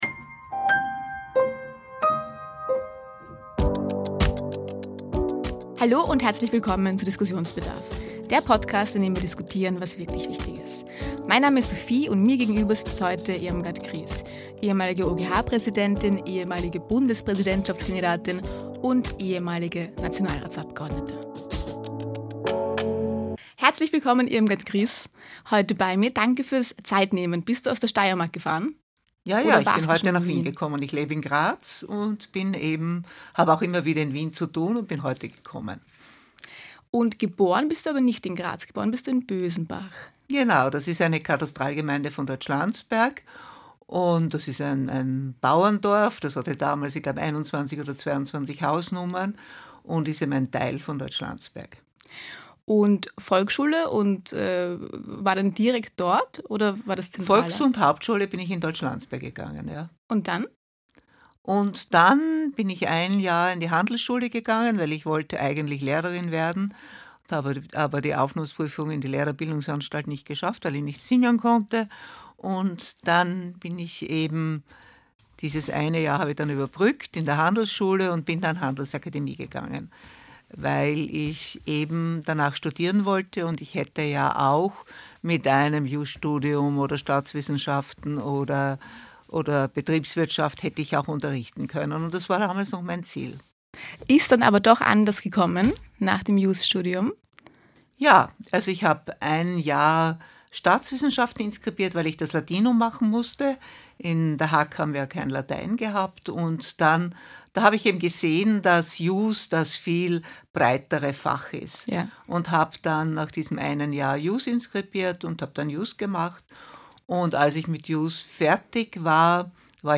Beschreibung vor 1 Jahr In dieser Folge "Diskussionsbedarf" sprechen wir mit Irmgard Griss, ehemalige OGH Präsidentin, ehemalige Bundespräsidentschaftskandidatin und ehemalige Nationalratsabgeordnete. Irmgard Griss spricht über ihren Weg von der Richterin zur OGH-Präsidentin und in die Politik. Sie teilt Erfahrungen aus dem Wahlkampf, kritisiert parteipolitische Postenbesetzungen und zeigt, was gute Verwaltung wirklich ausmacht. Ein Gespräch über Rechtsstaat, Vertrauen und die Frage, wie Demokratie wieder funktionieren kann – und vieles mehr.